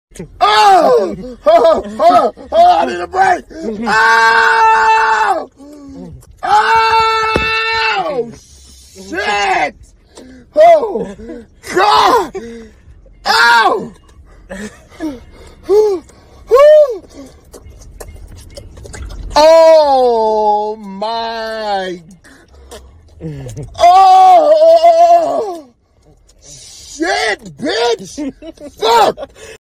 Tower out of dominoes sound effects free download